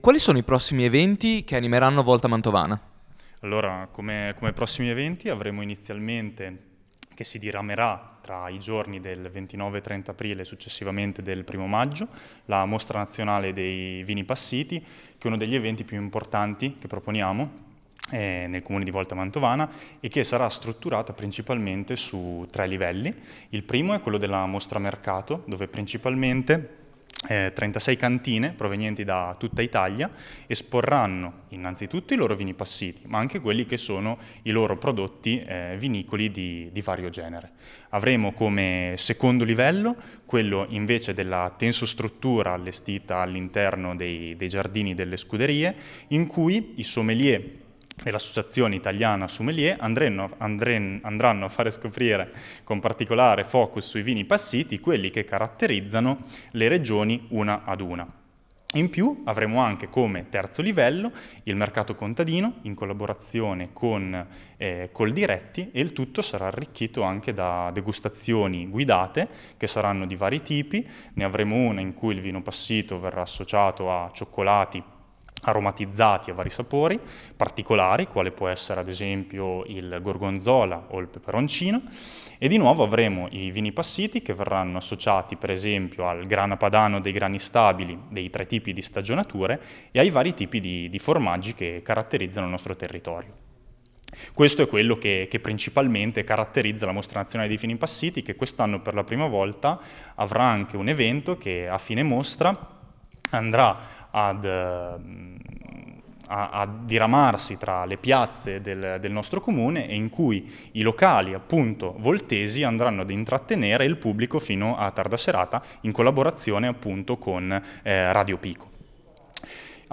Ecco, ai nostri microfoni, le dichiarazioni raccolte durante la serata: